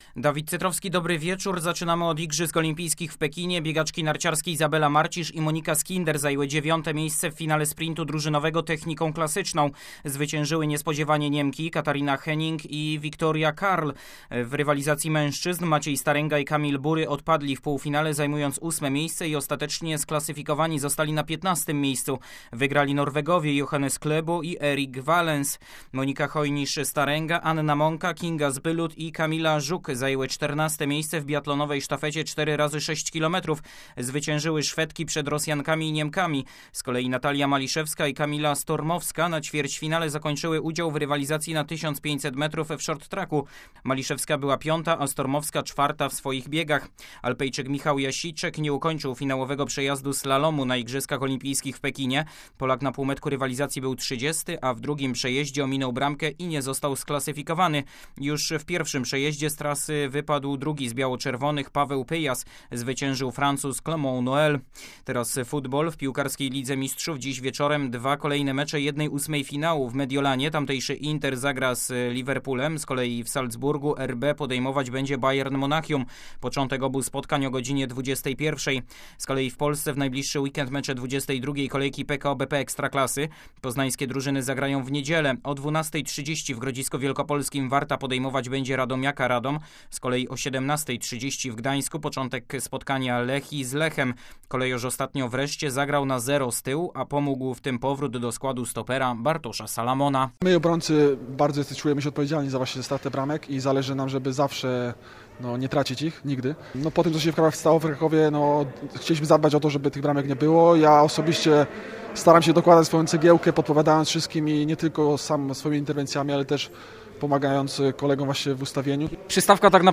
16.02.2022 SERWIS SPORTOWY GODZ. 19:05
W środowym serwisie sportowym rozmowa z szefem obrony Lecha Poznań Bartoszem Salamonem. A oprócz codziennych informacji z igrzysk w Pekinie także o zbliżającym się turnieju finałowym koszykarskiego Pucharu Polski.